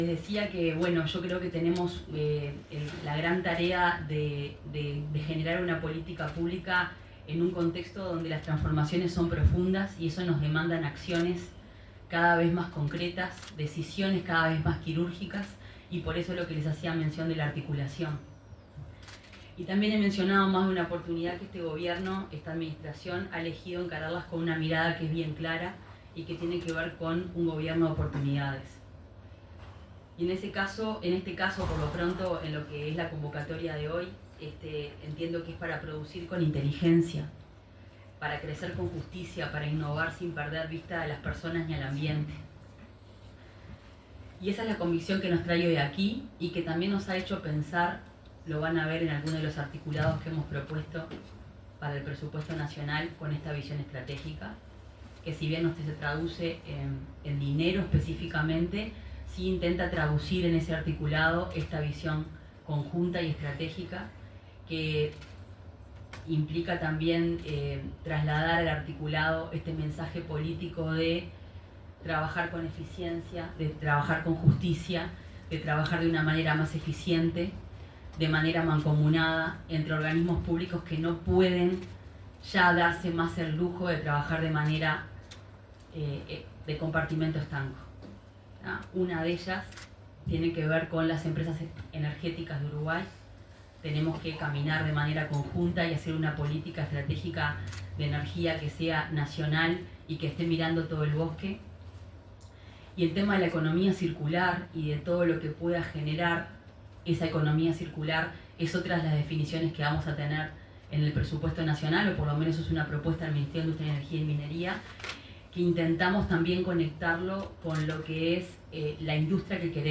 Palabras de la ministra de Industria, Fernanda Cardona
La ministra de Industria, Energía y Minería, Fernanda Cardona, expuso en el lanzamiento del programa Oportunidades Circulares 2025 de la Agencia